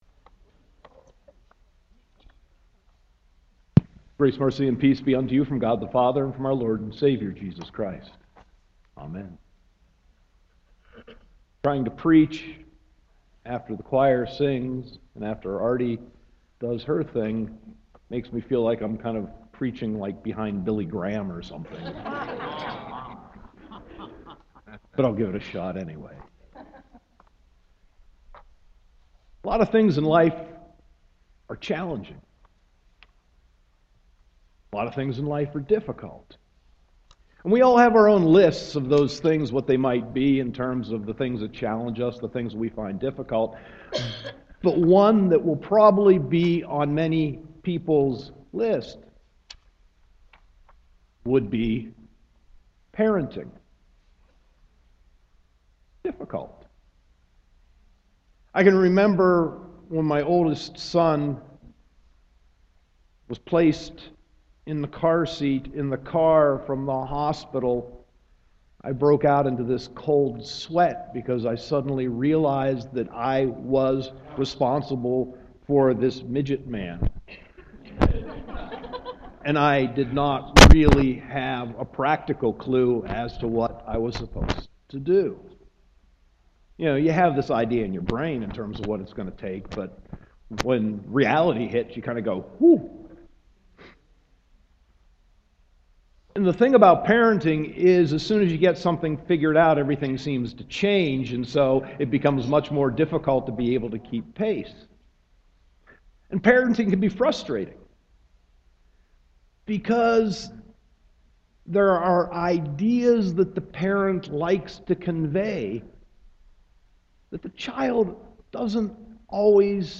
Sermon 10.18.2015